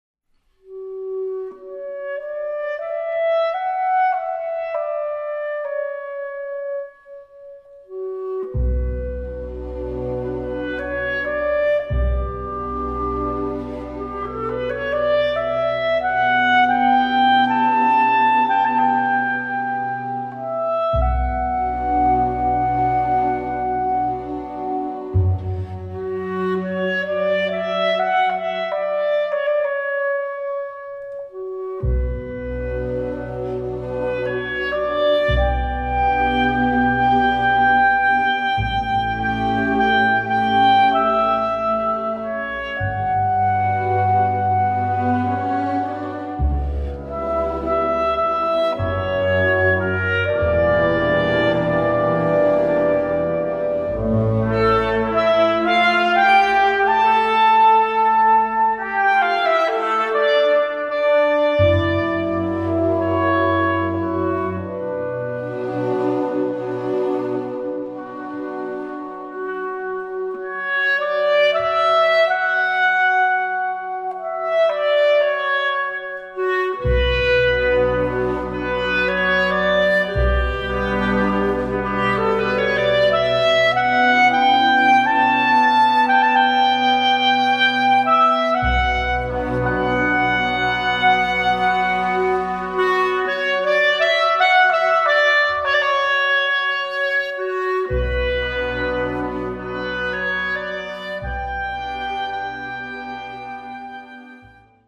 Voicing: Clarinet and Orchestra